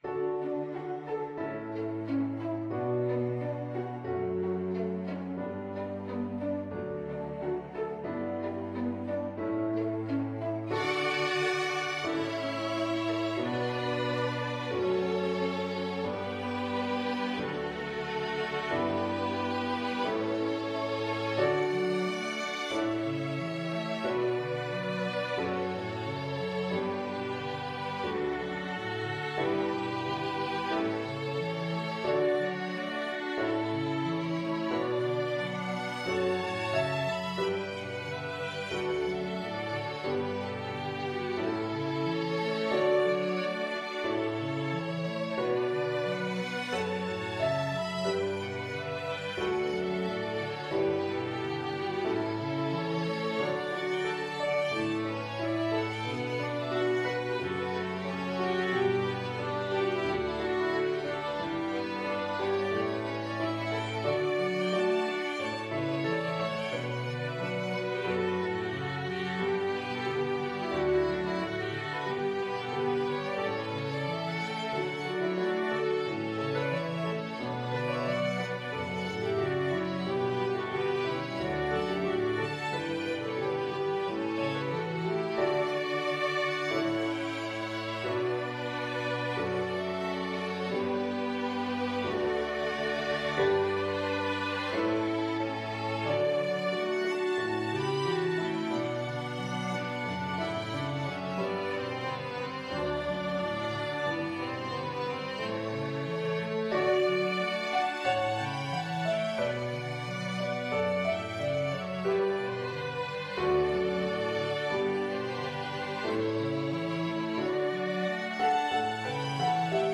Piano Quintet version
Violin 1Violin 2ViolaCelloPiano